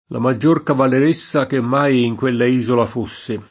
cavalieressa [kavalLer%SSa] s. f. (scherz. «moglie di cavaliere») — ant. cavaleressa [kavaler%SSa]: la maggior cavaleressa che mai in quella isola fosse [